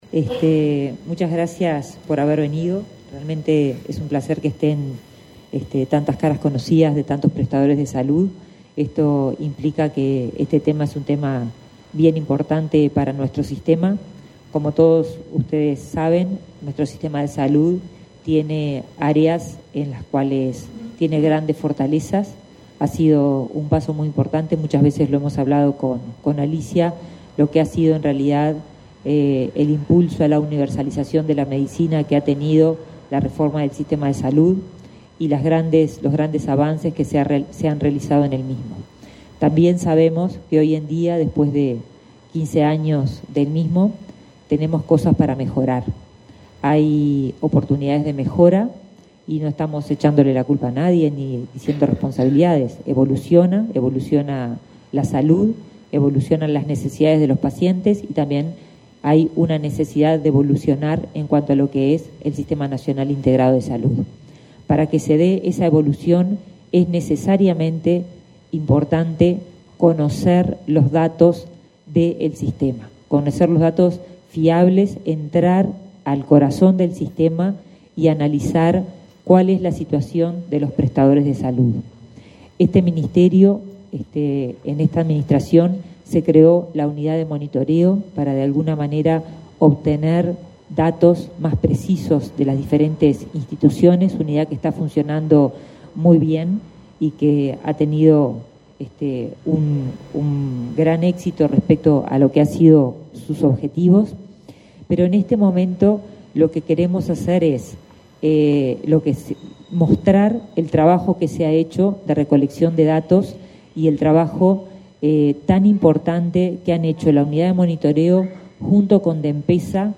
Palabras de la ministra de Salud Pública, Karina Rando, y del subsecretario José Luis Satdjian
Palabras de la ministra de Salud Pública, Karina Rando, y del subsecretario José Luis Satdjian 10/12/2024 Compartir Facebook X Copiar enlace WhatsApp LinkedIn El Ministerio de Salud Pública presentó, este 10 de diciembre, las proyecciones e indicadores en áreas como la cobertura sanitaria y los tiempos de espera, entre otros datos. En el evento, la ministra Karina Rando, y el subsecretario, José Luis Satdjian, realizaron declaraciones.